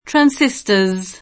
[ tran S i S t 1 re ]